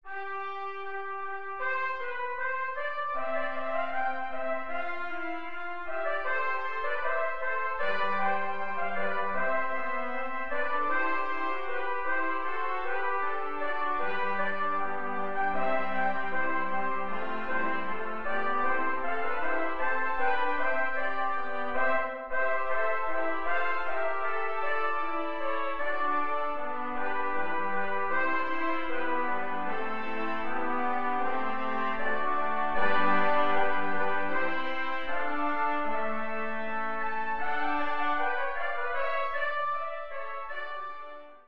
for 8 – 16 Trumpets